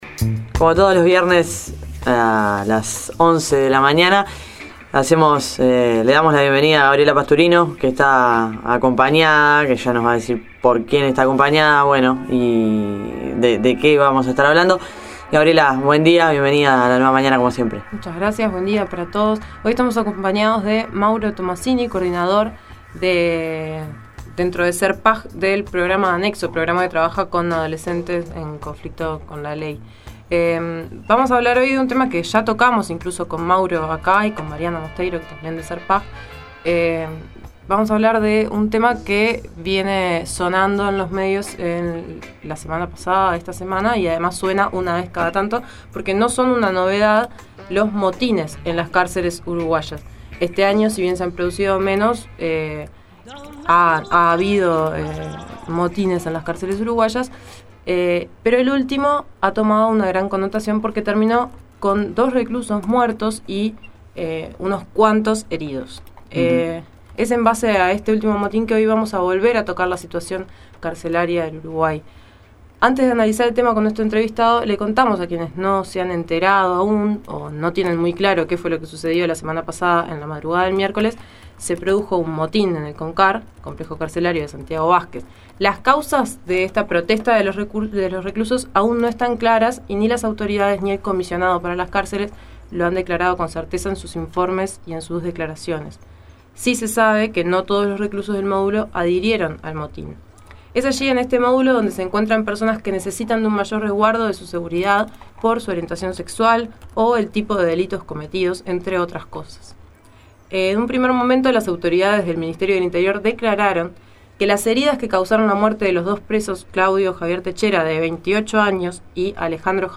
Audio: Situacion carcelaria en Uruguay. Entrevista